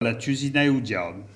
Localisation Sallertaine
Locutions vernaculaires